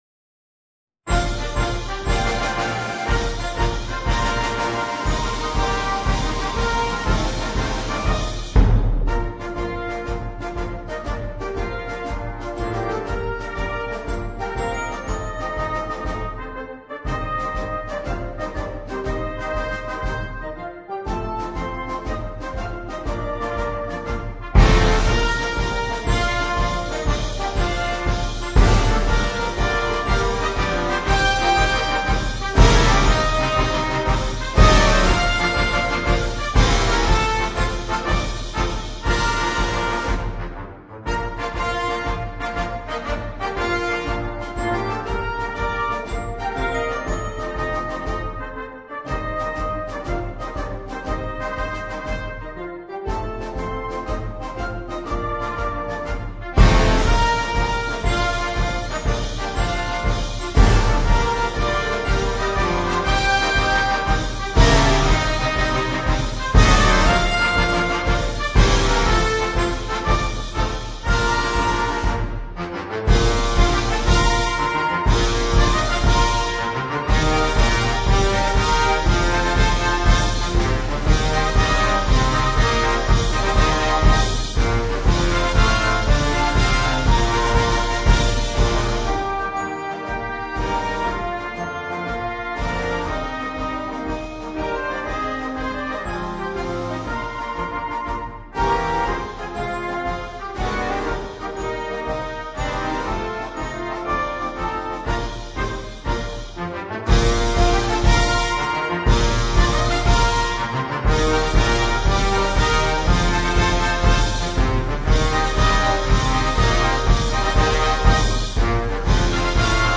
MARCE DA SFILATA
MUSICA PER BANDA
Marcia militare